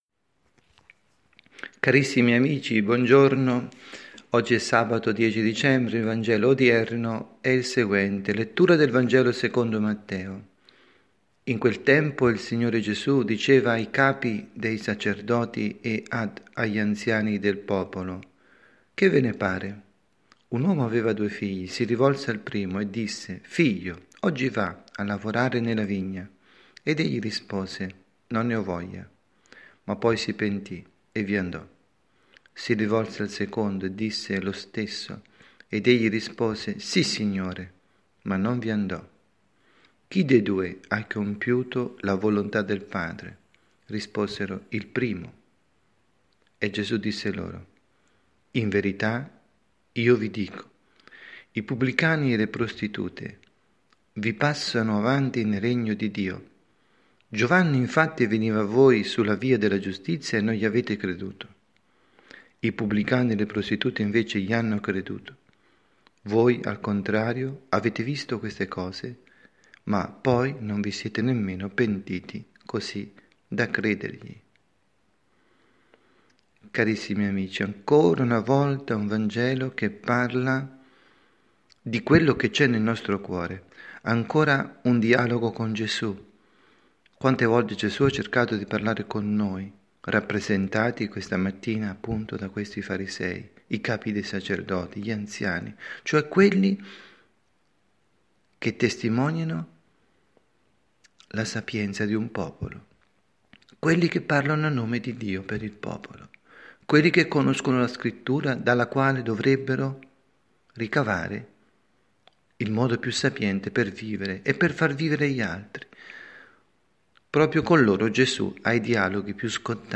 Omelia
dalla Parrocchia S. Rita, Milano